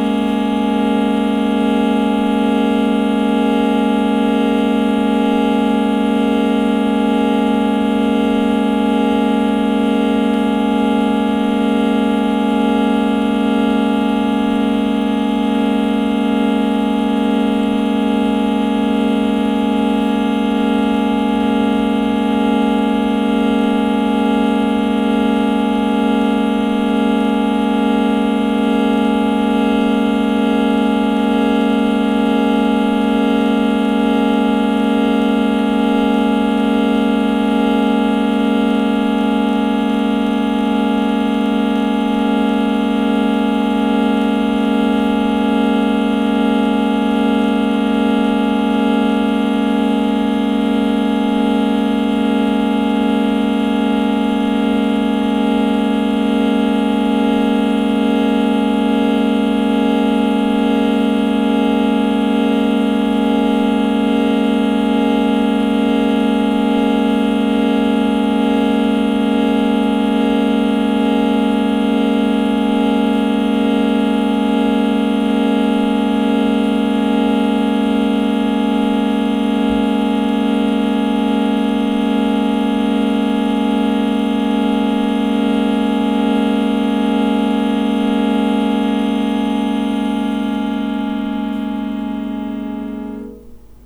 drone6.wav